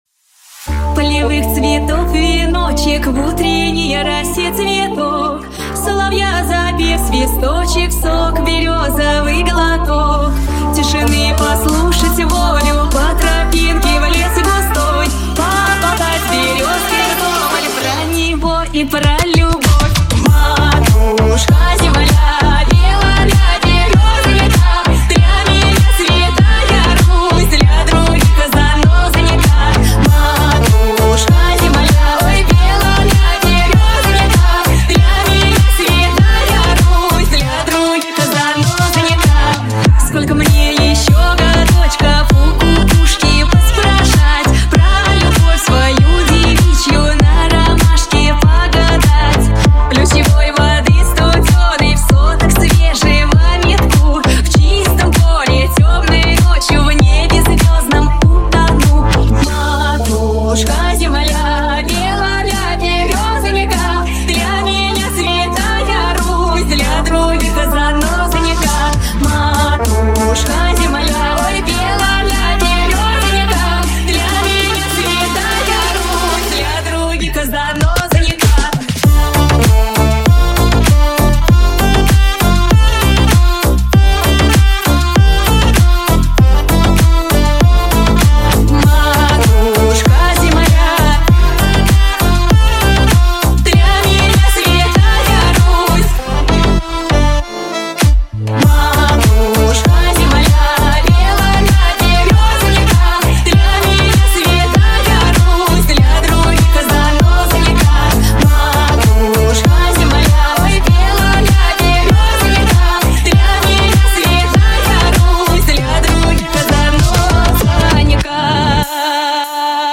• Категория: Новые ремиксы